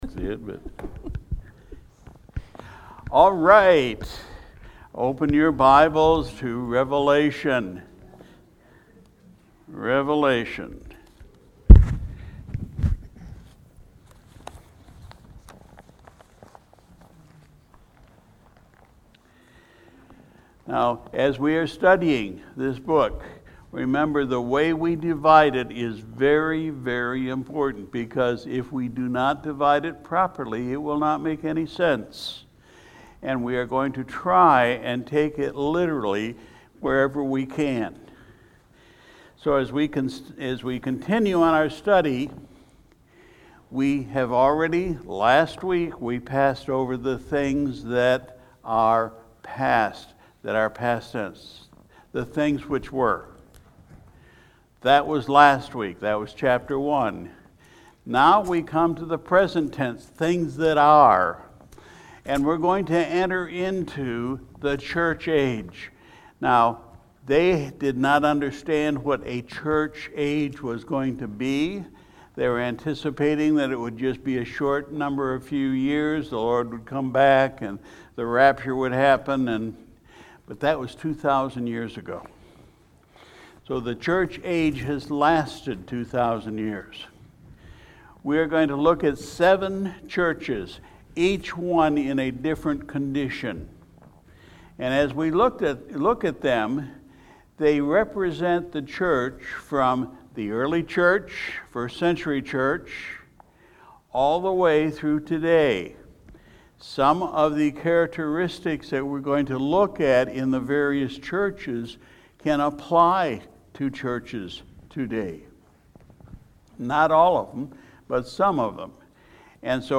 December 12, 2021 Sunday Evening Service We continued our study in the Book of Revelation (Revelation 2:1-11)